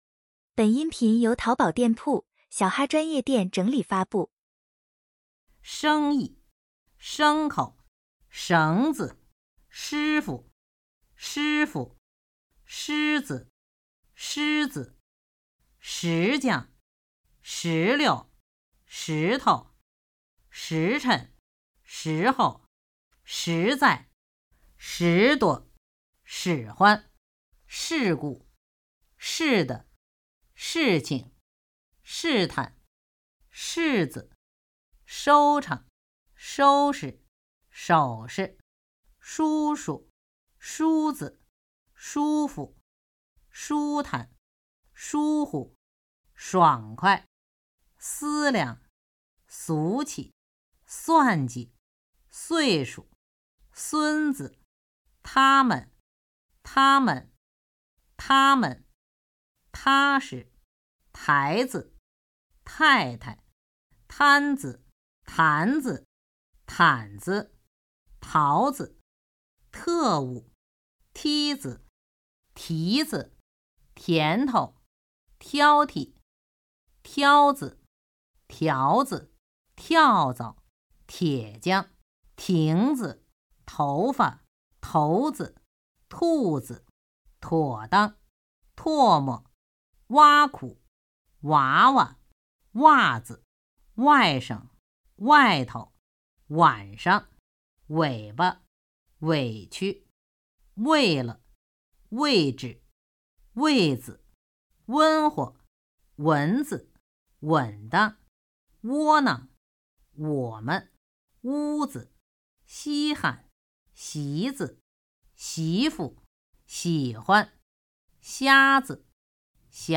轻声401到500.mp3
普通话水平测试 > 普通话水平测试资料包 > 01-轻声词语表